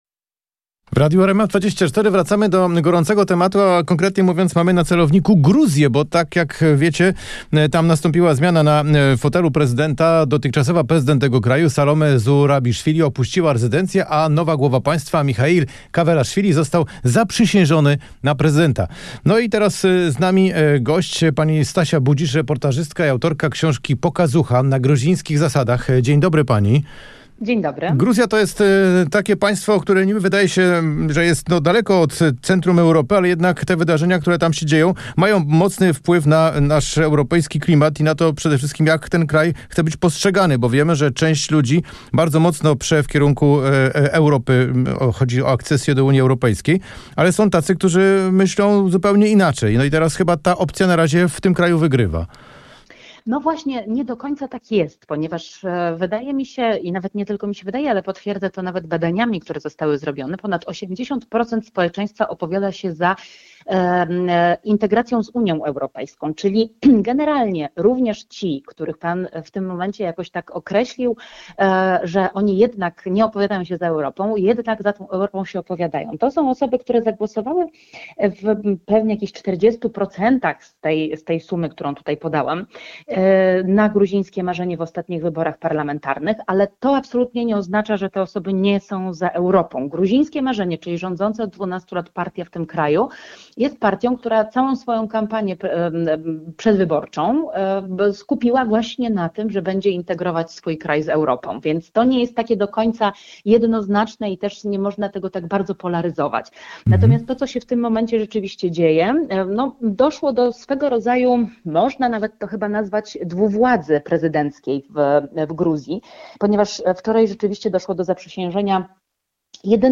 Dziennikarze Radia RMF24 wraz z ekspertami rzeczowo i konkretnie komentują najważniejsze tematy; tłumaczą zawiłości polityki i ekonomii; pomagają zrozumieć, co dzieje się w Polsce i na świecie.